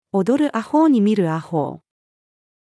踊る阿呆に見る阿呆-female.mp3